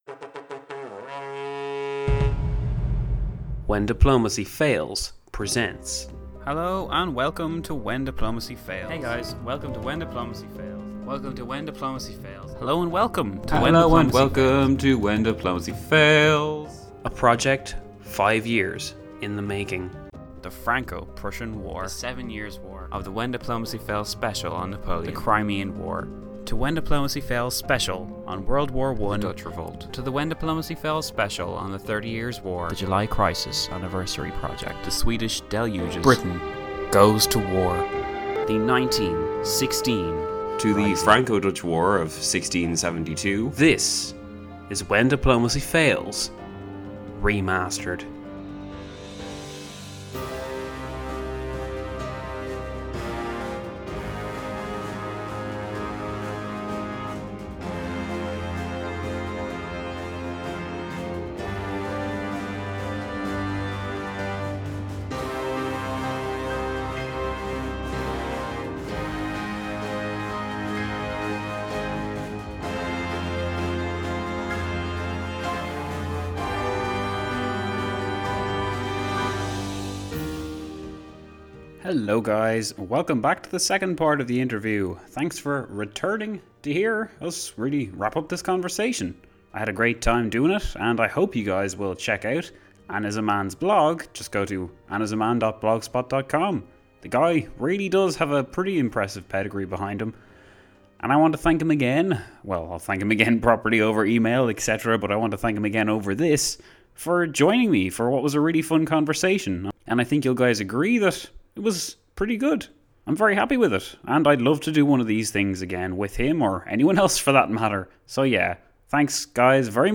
This collab was a true conversation and not just a basic Q&A. I found myself being drawn in by the discussion and caught myself thinking about what I like in a podcast.